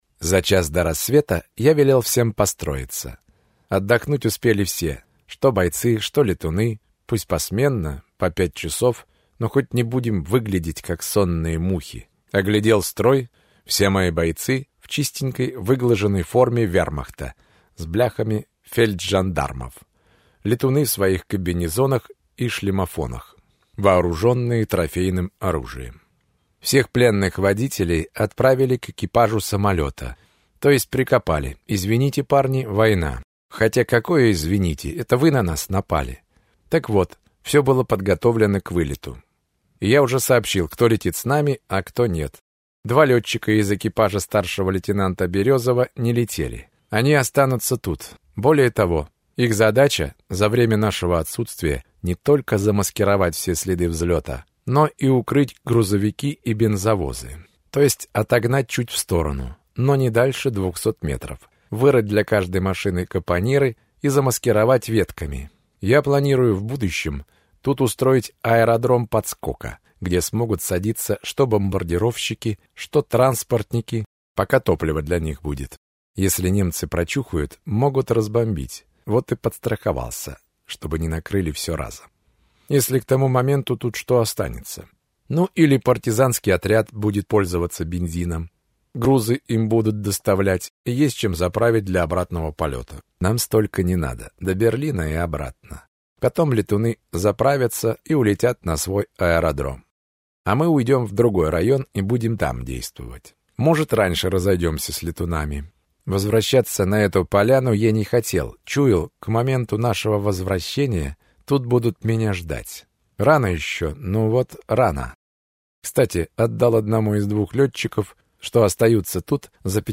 Владимир Поселягин. Прорыв. Аудиокнига